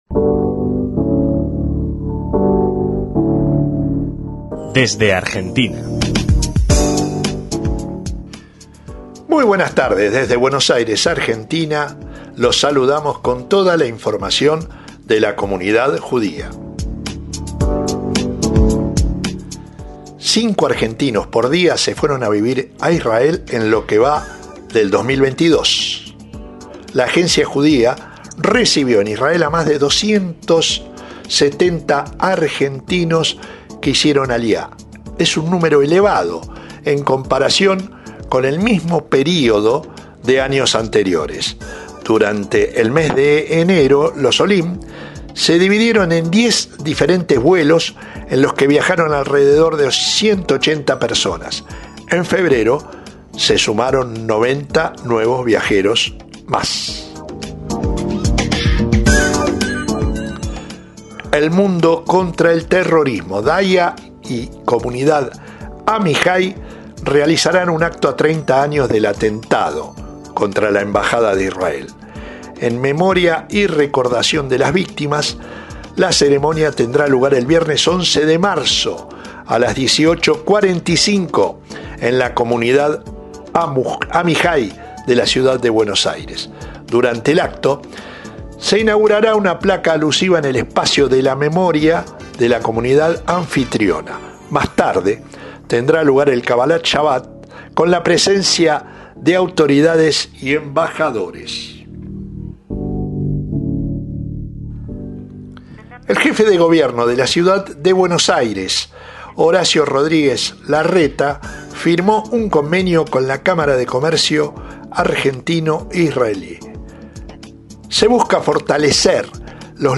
Un nuevo informe bisemanal llega a nosotros desde la redacción de Vis a Vis en Argentina